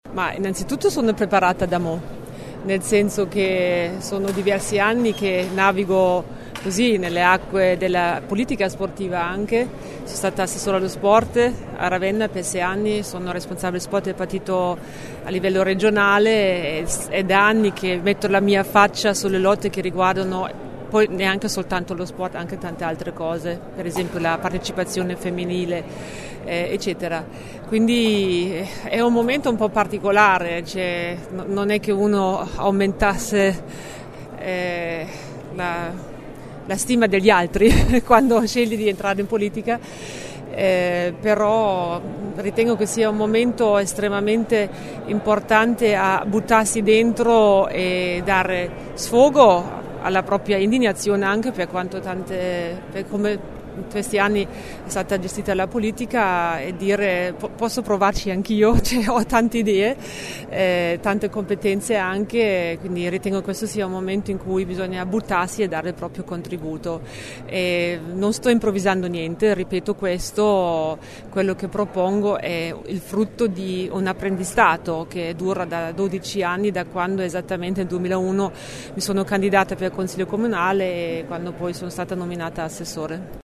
Questi gli ingredienti della presentazione di questa mattina dei candidati nelle liste emiliano romagnole del Partito Democratico alle prossime elezioni politiche.